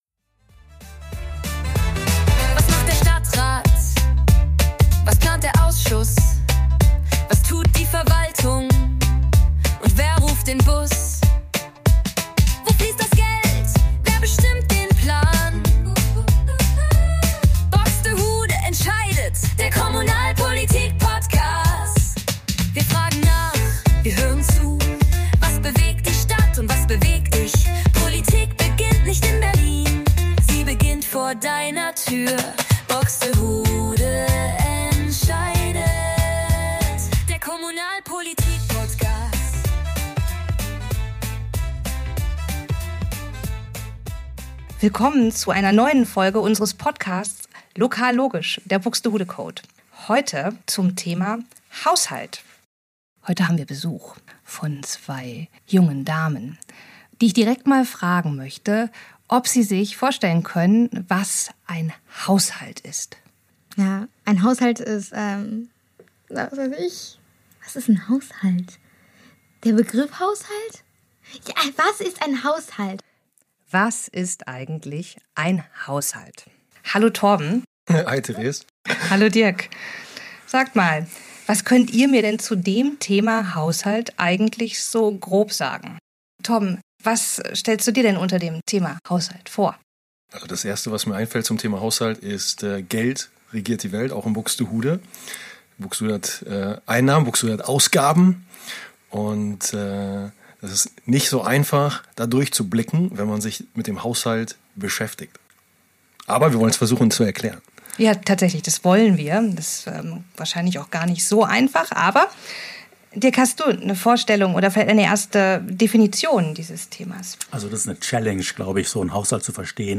Wir sprechen darüber, was der Haushalt ist, wofür Buxtehude in unserem Bürger:innen-Sinne Geld ausgibt, wo das Geld herkommt und wie man als Einwohnende der Stadt auf den Haushalt Einfluss nehmen kann. Dieses mal freuen wir uns sehr darüber Kai Seefried, den Chef der Kreisverwaltung, als Gast begrüßen zu dürfen. Kai Seefried hilft uns bei der Klärung von Fragen rund um den Haushalt!